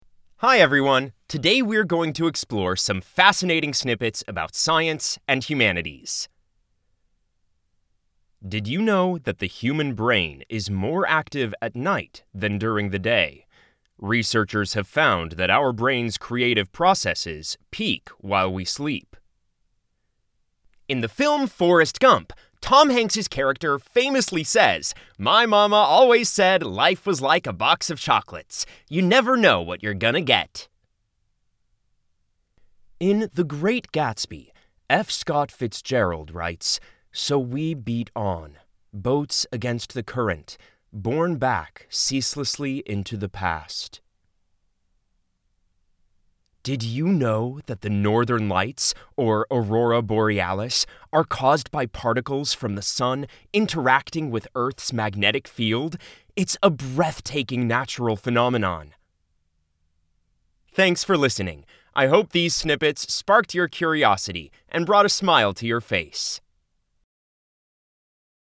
Where voices are not provided, as is typically the case for open source models, we use voices clips from professional voice actors as source files for generating speech.